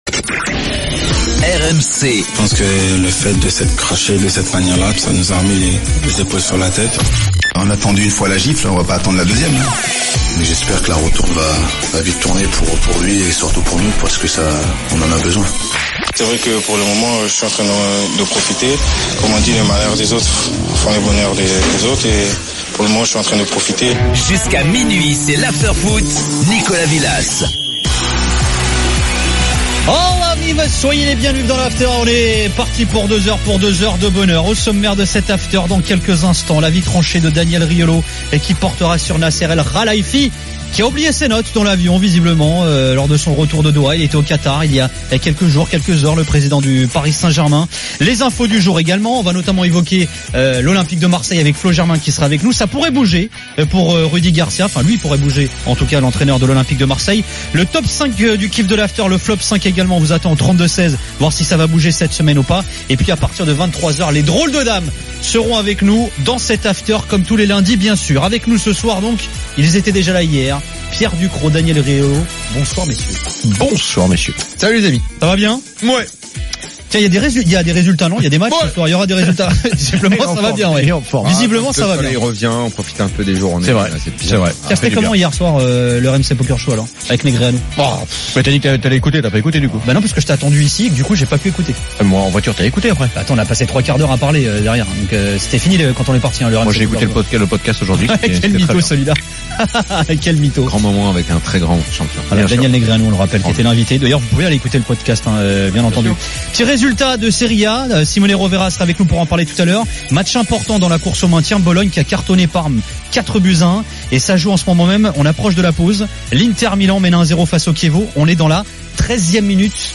Après le match, avec Gilbert Brisbois, Daniel Riolo et Jérôme Rothen, le micro de RMC est à vous !